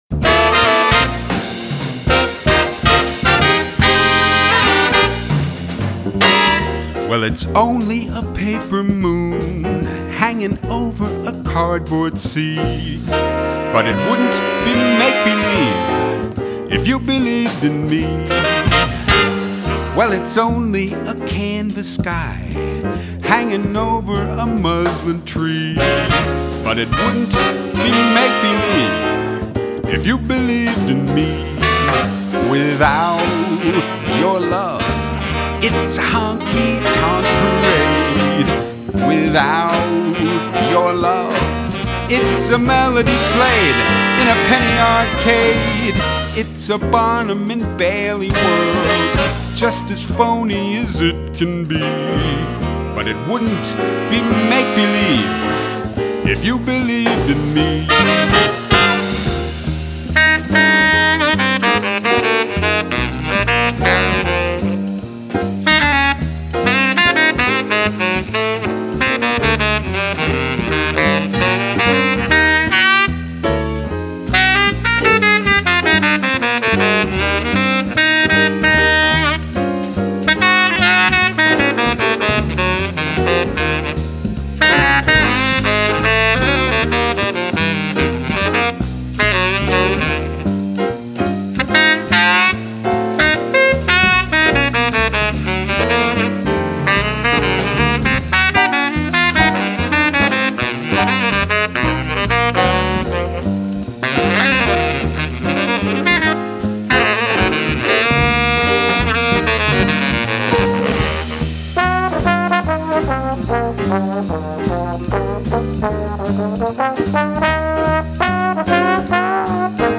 little big band
some of the better soloists in Hamburg